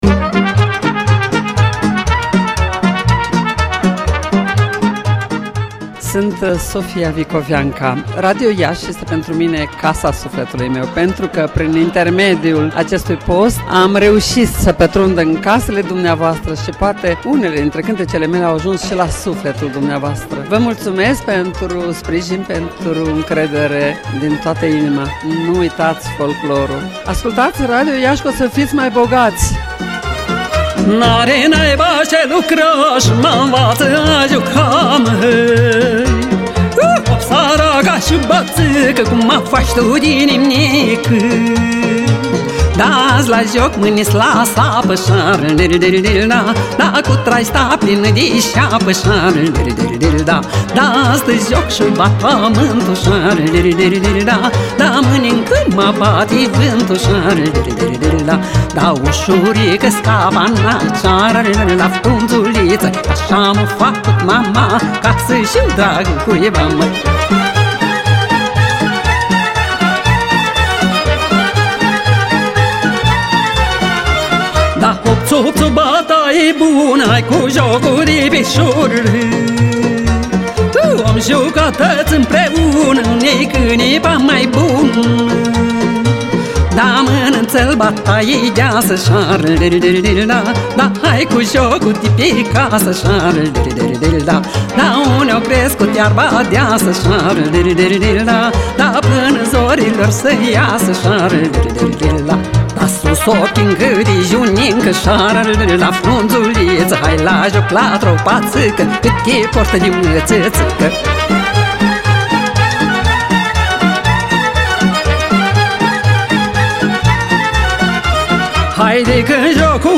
MUZICĂ Sofia Vicoveanca și SALUT adresat publicului Radio Iași … 2’44’’